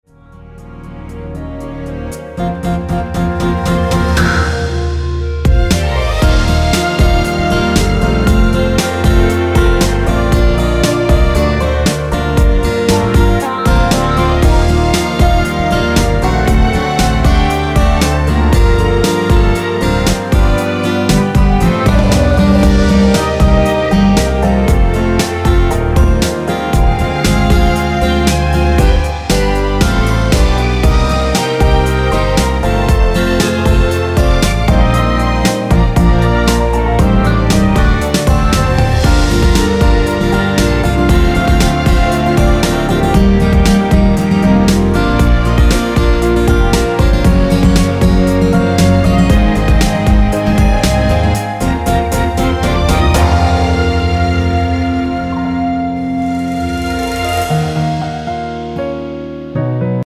원키에서(-2)내린 (1절+후렴)으로 진행되는 멜로디 포함된 MR입니다.
Gm
앞부분30초, 뒷부분30초씩 편집해서 올려 드리고 있습니다.
(멜로디 MR)은 가이드 멜로디가 포함된 MR 입니다.